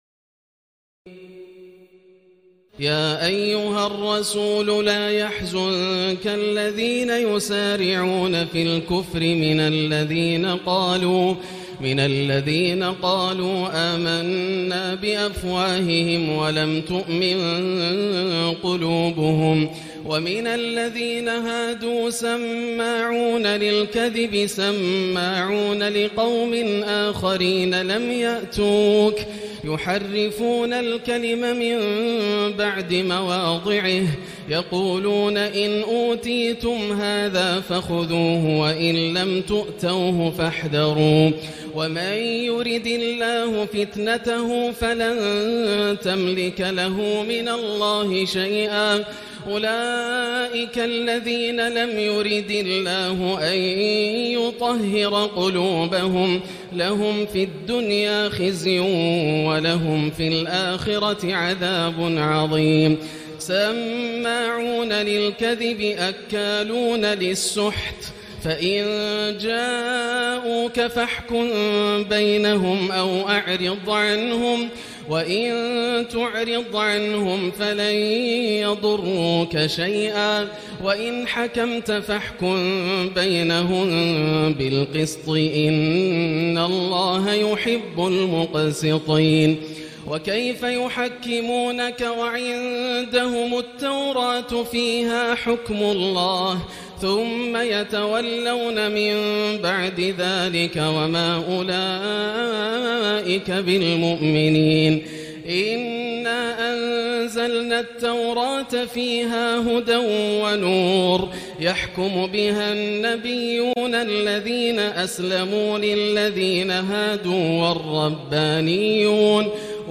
تراويح الليلة السادسة رمضان 1439هـ من سورة المائدة (41-105) Taraweeh 6 st night Ramadan 1439H from Surah AlMa'idah > تراويح الحرم المكي عام 1439 🕋 > التراويح - تلاوات الحرمين